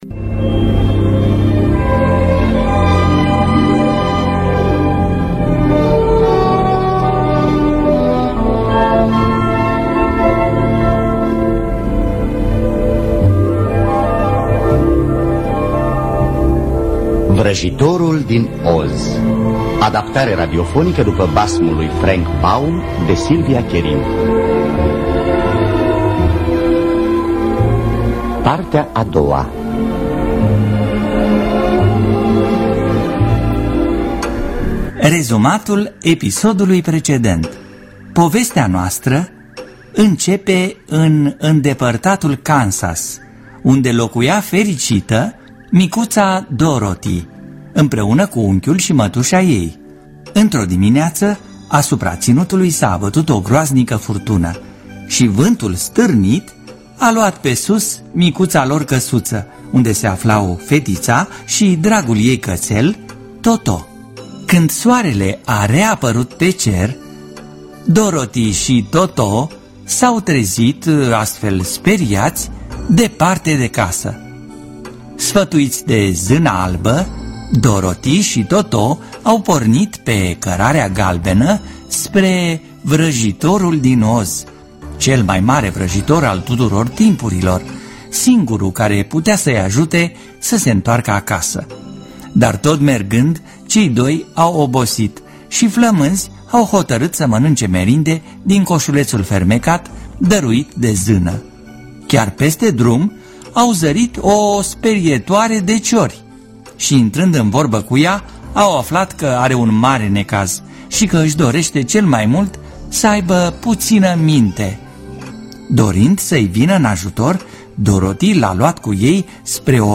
“Vrăjitorul din Oz” de Lyman Frank Baum. Partea a II-a. Adaptarea radiofonică de Silvia Kerim.